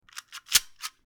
Reloading A Gun
Reloading_a_gun.mp3